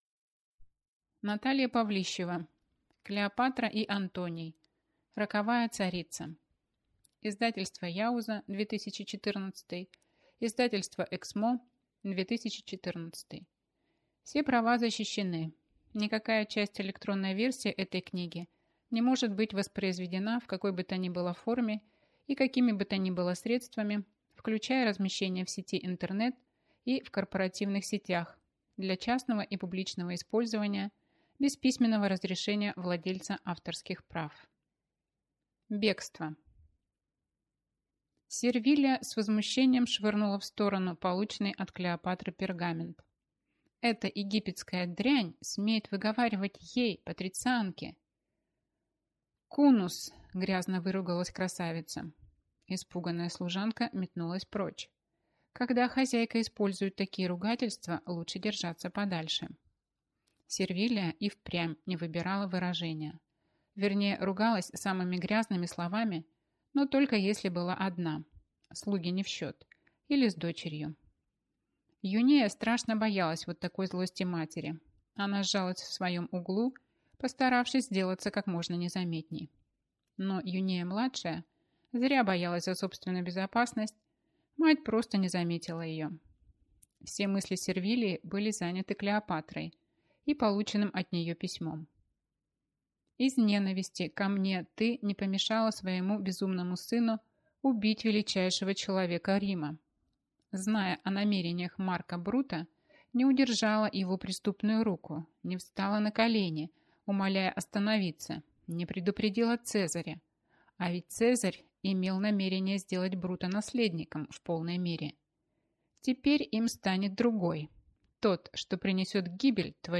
Аудиокнига Клеопатра и Антоний. Роковая царица | Библиотека аудиокниг